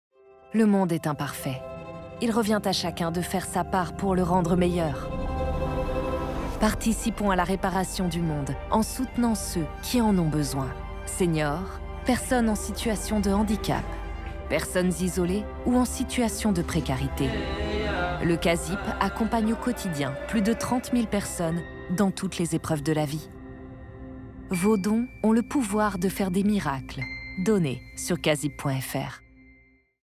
Voix off
Institutionnel Casip
25 - 45 ans - Mezzo-soprano